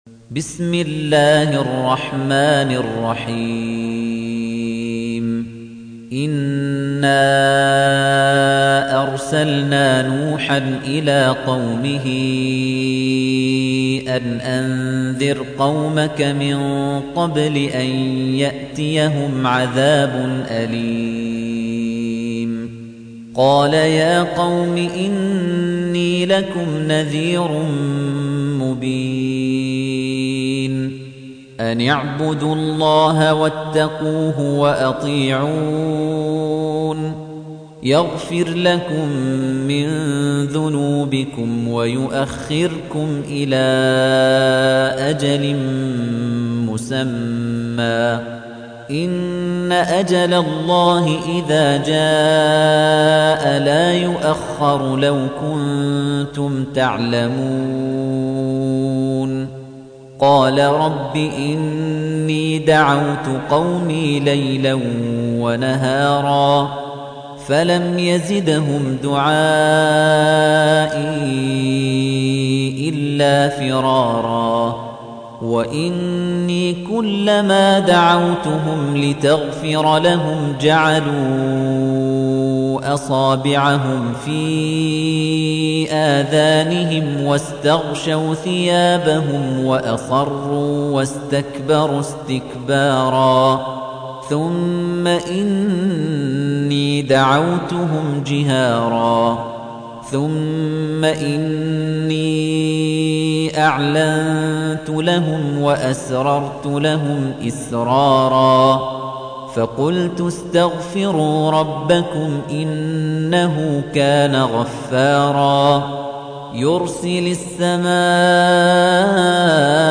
تحميل : 71. سورة نوح / القارئ خليفة الطنيجي / القرآن الكريم / موقع يا حسين